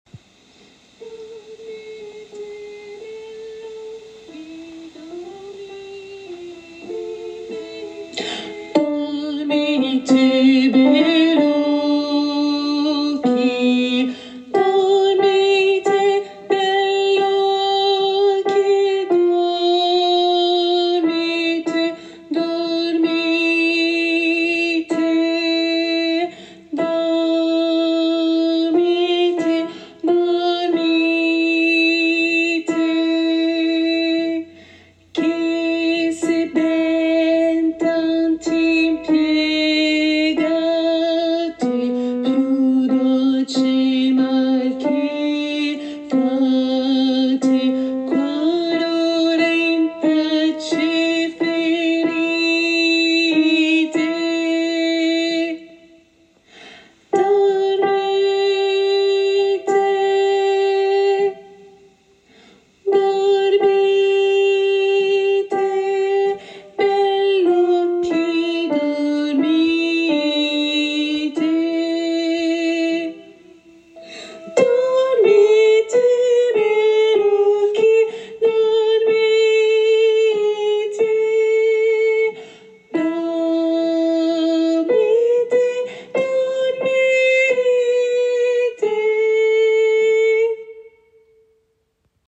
Alto et autres voix en arrière